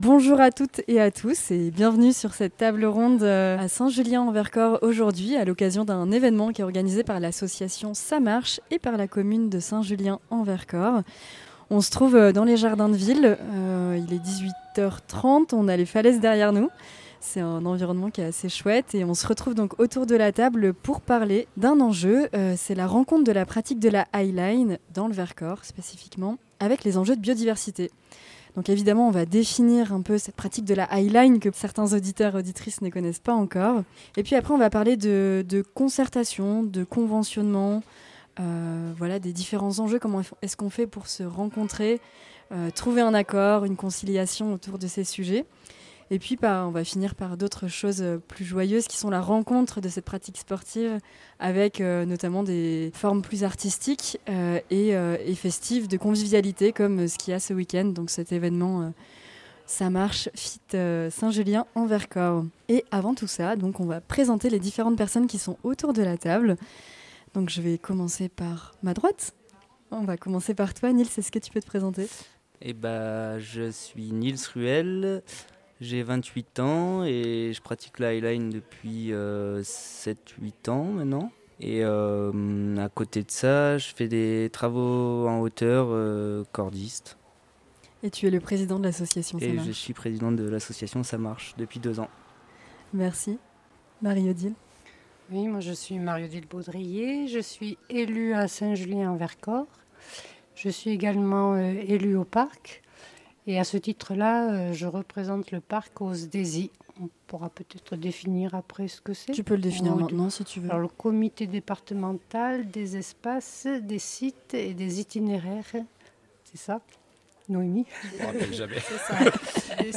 Discussion radiophonique autour de la pratique de la highline dans le Vercors et les enjeux de biodiversité, à Saint Julien en Vercors le 12 septembre 2025. Concertation, charte, visites de sites, zones de quiétudes… tour d’horizon des différents outils développés localement !